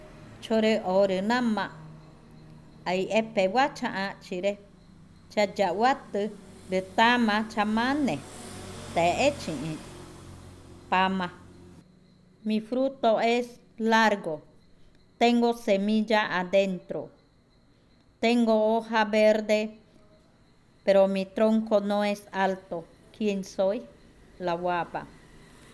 Adivinanza 13. La guaba
Cushillococha